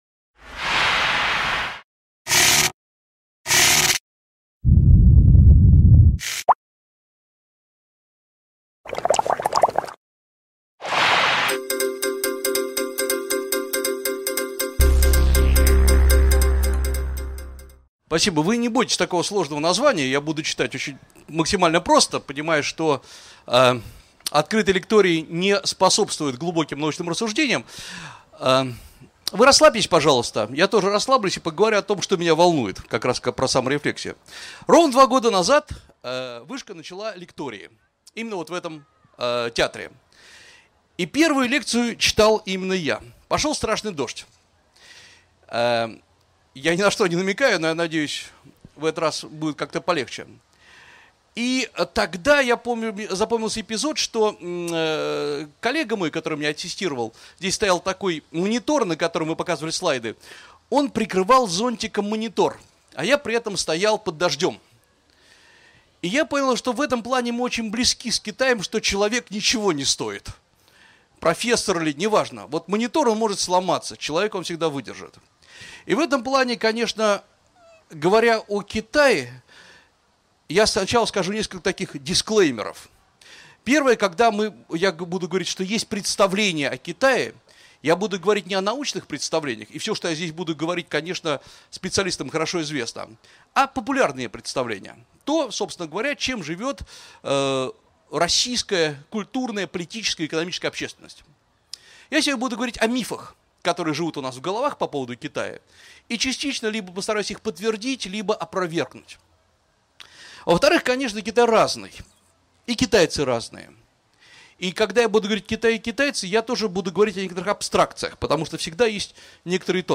Аудиокнига Китай как саморефлексия российской политической культуры | Библиотека аудиокниг